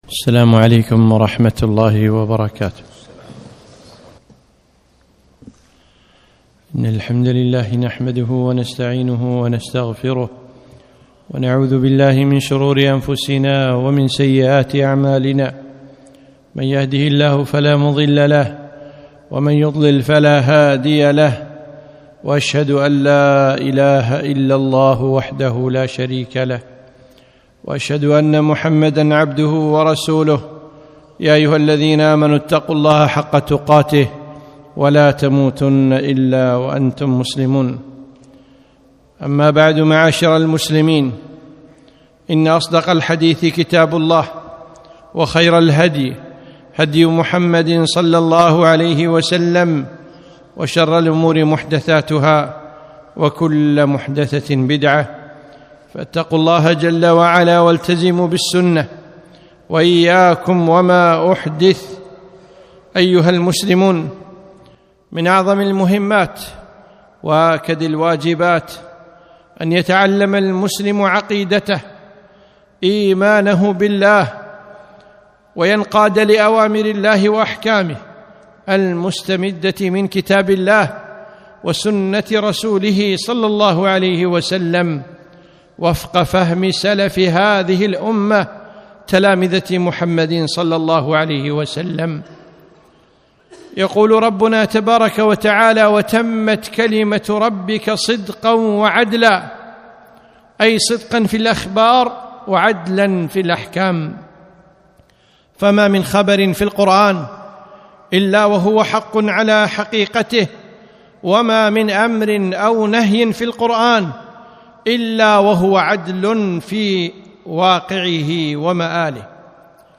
خطبة - رؤية الله تعالى - دروس الكويت